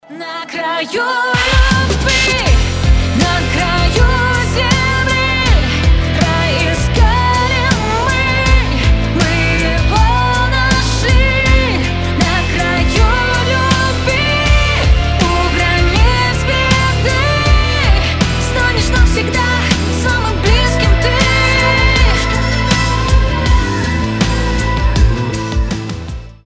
громкие
женский голос